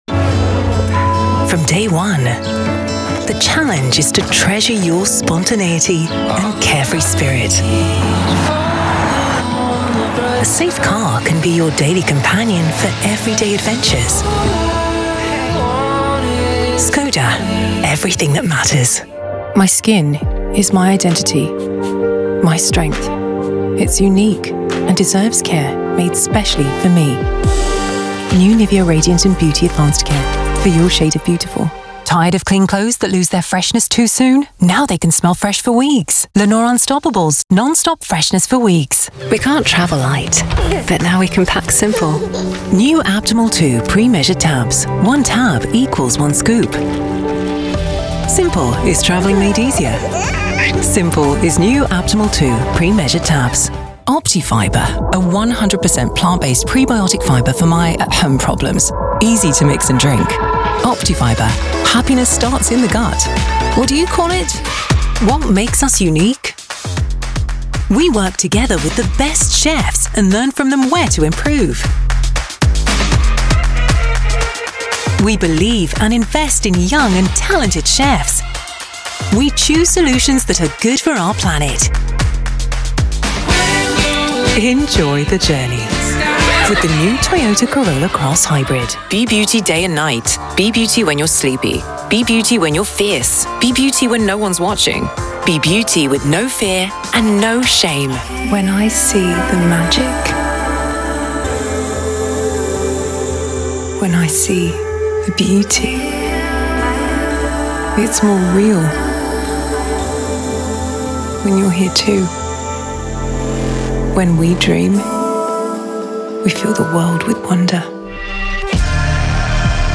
Voix off
British | Commercial Reel